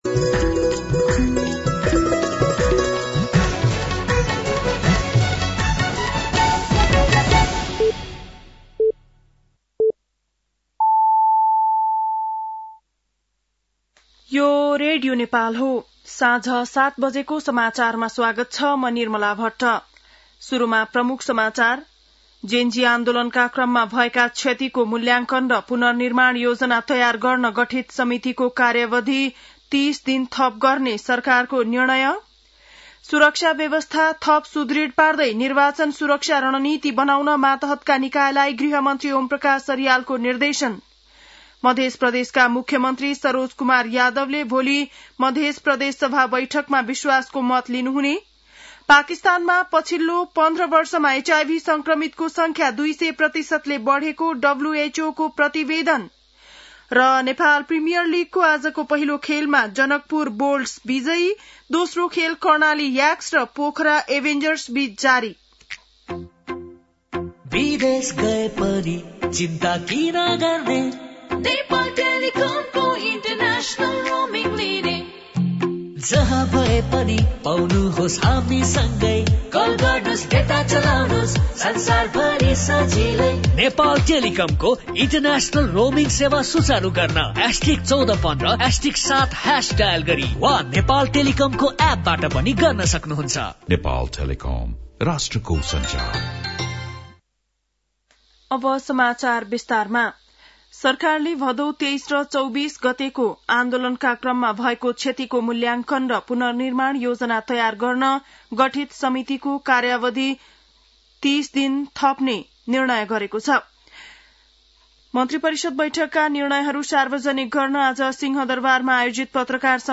An online outlet of Nepal's national radio broadcaster
बेलुकी ७ बजेको नेपाली समाचार : १६ मंसिर , २०८२